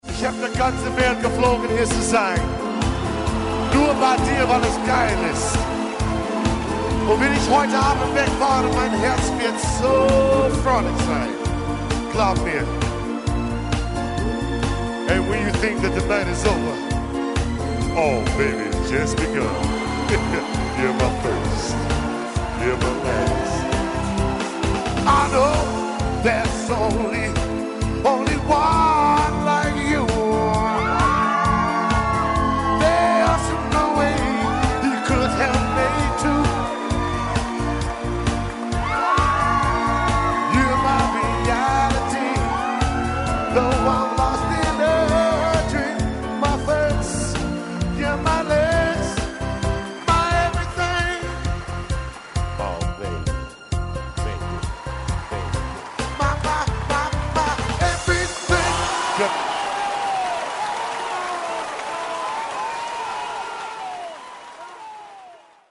Ein Muss für alle Freunde von Soulhits und Rockballaden.
live